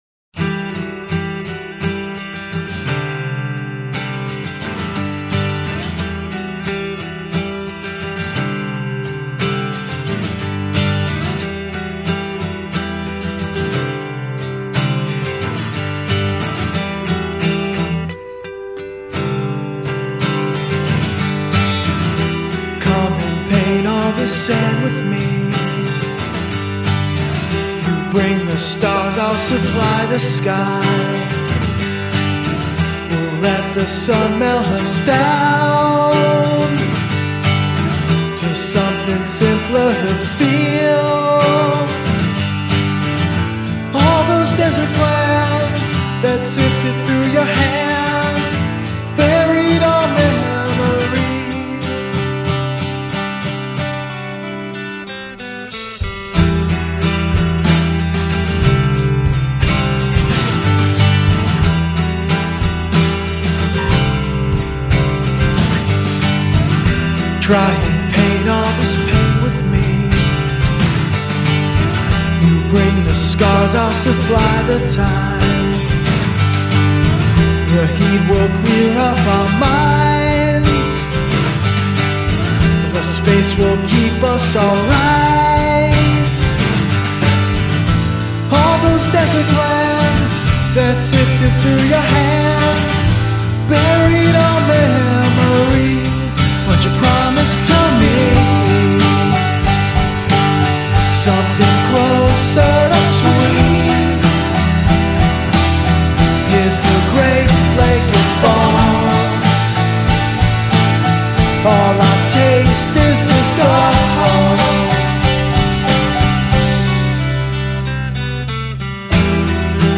Plaintive. Simple. Decidedly American.